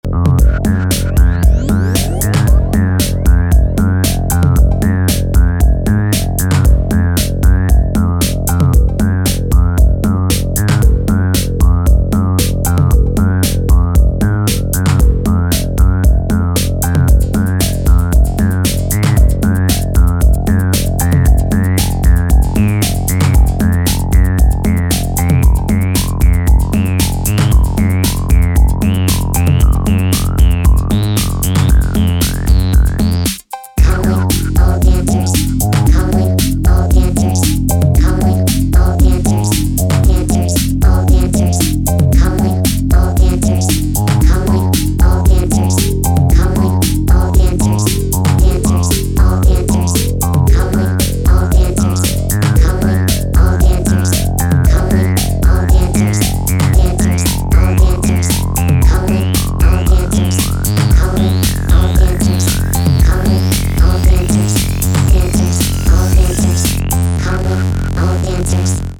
よりクラシカルなエレクトロ・ビートでアプローチを変えた